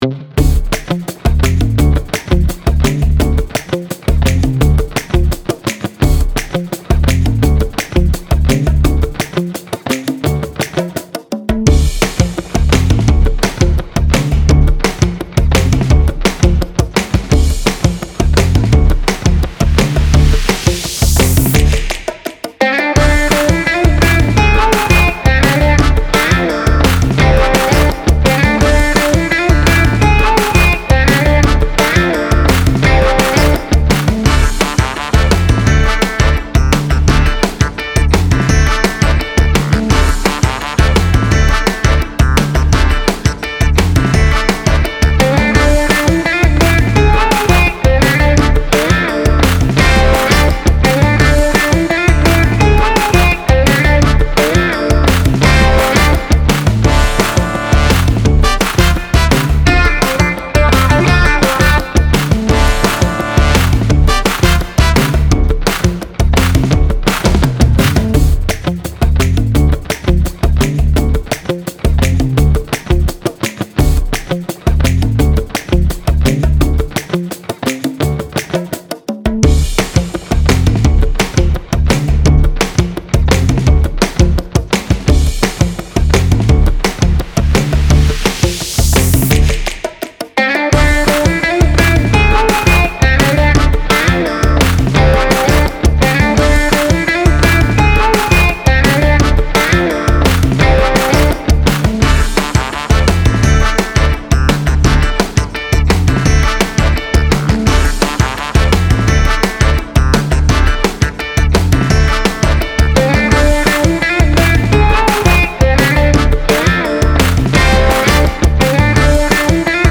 体が動き出しそうなノリの良いファンク